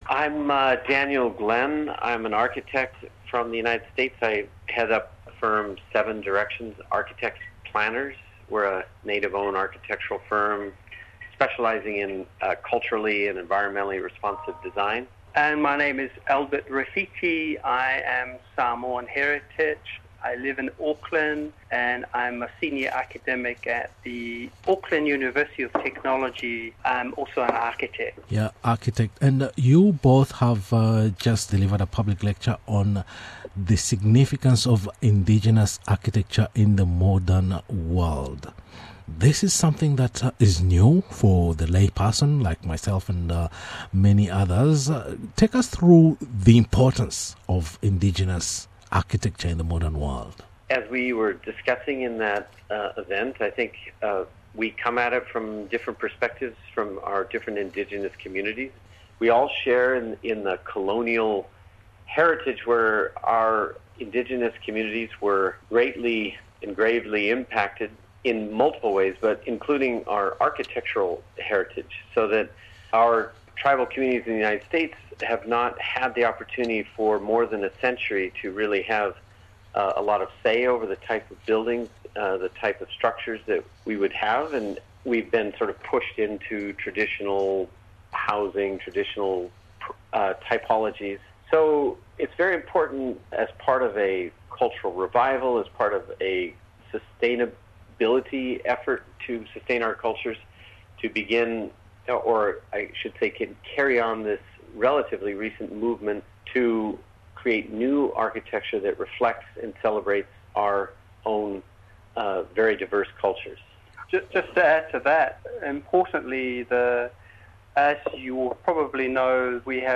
A public lecture was organized by the University of Queensland to discuss the place of Indigenous architecture in the modern world. As architecture is increasingly being used as a method of reinforcing Indigenous identity and cultural regeneration around the world.